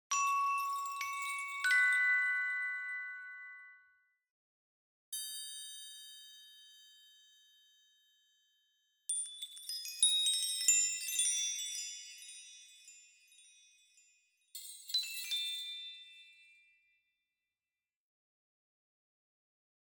• Geluid: 15 - 20 feestelijke kerstdeuntjes